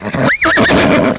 whinny.mp3